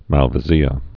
(mălvə-zēə)